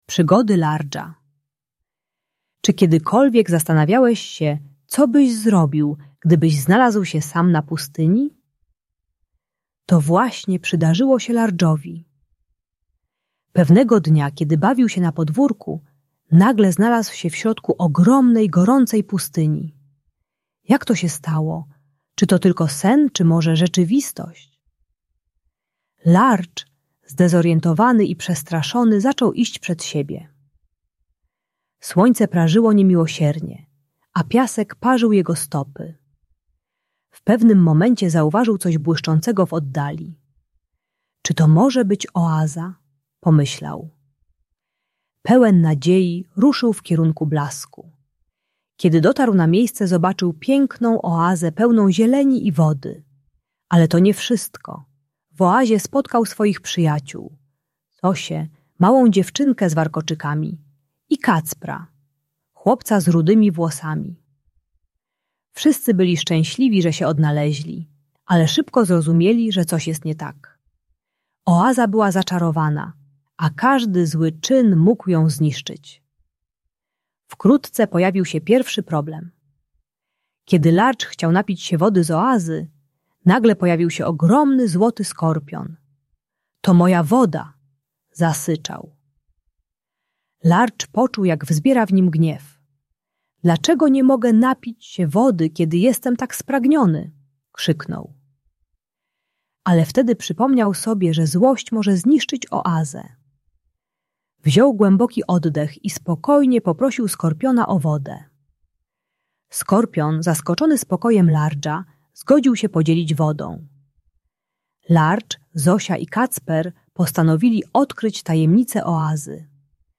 Przygody Lardża - Agresja do rodziców | Audiobajka